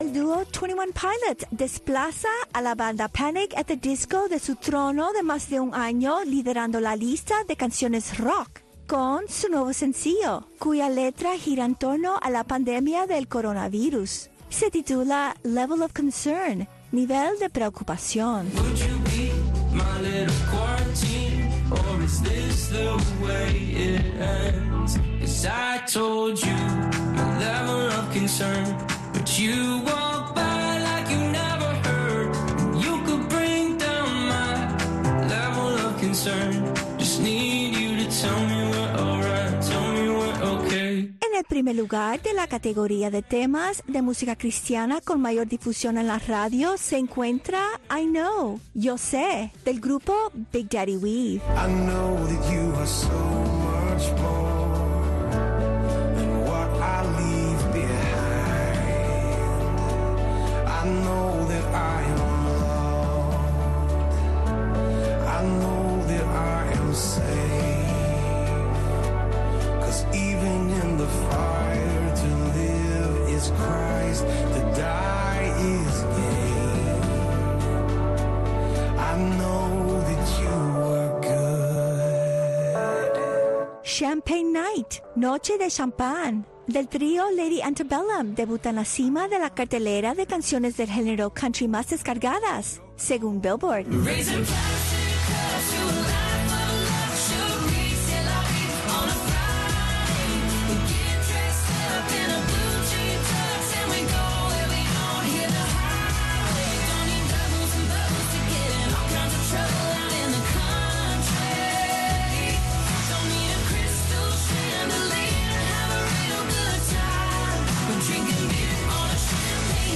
Revista informativa con entrevistas, comentarios, entretenimiento y música en vivo